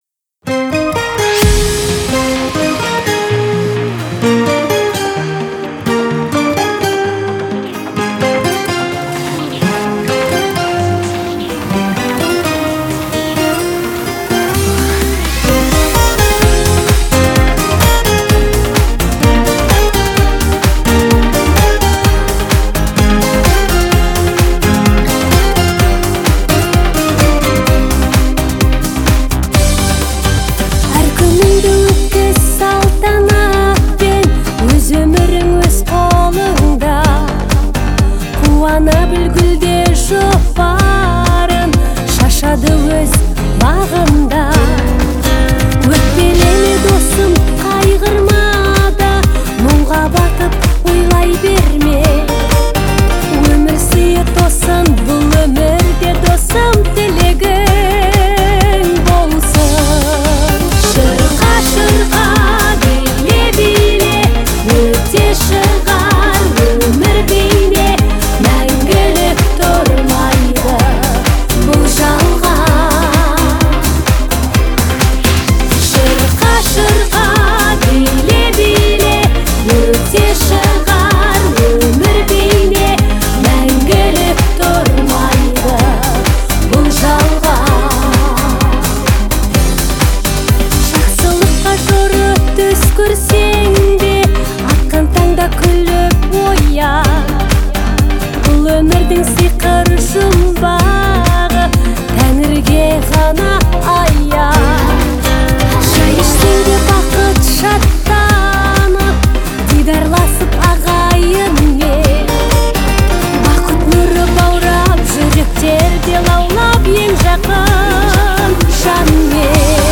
мощный вокал